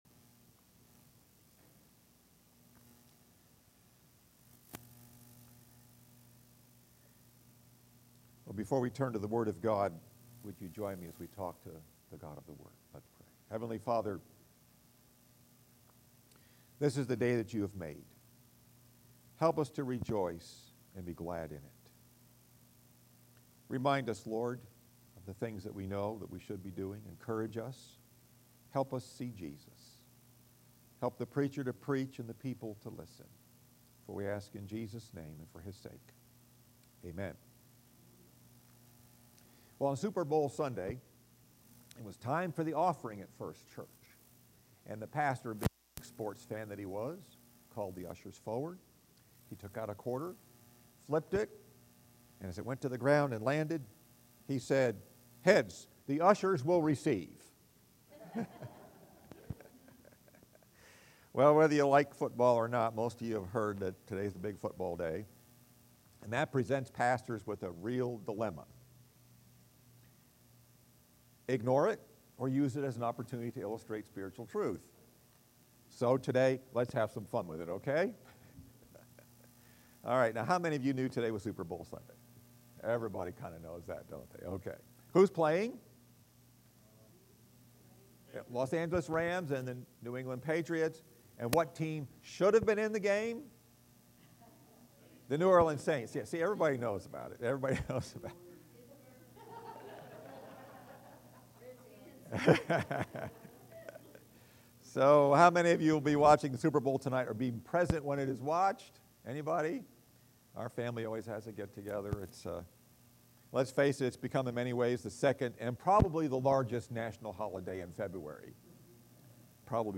Message: “The Biggest Game of All” Scripture: Philippians 3:7-14 SIXTH SUNDAY AFTER CHRISTMAS See the sermon video illustration used in the audio message above.